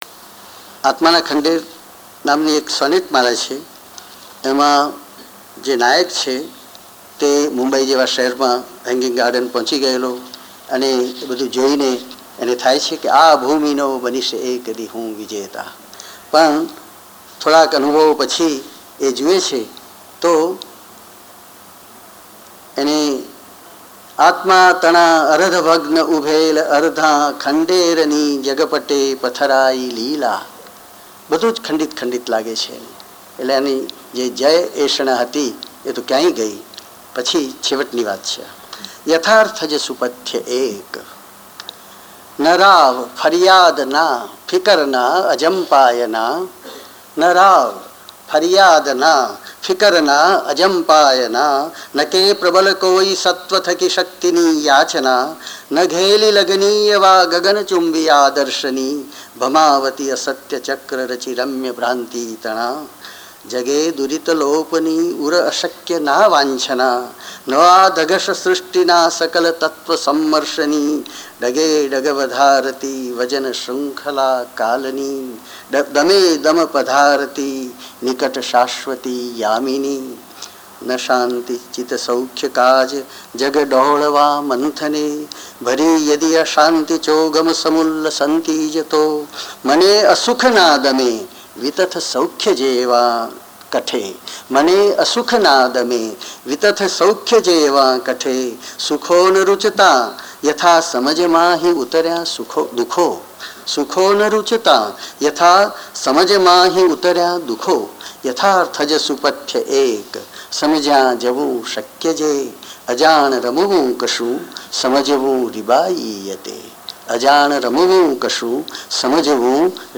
આત્માનાં ખંડેર: સૉનેટમાલા • કવિના સ્વમુખે કાવ્યપઠન from ઉમાશંકર જોશી • આત્માનાં ખંડેર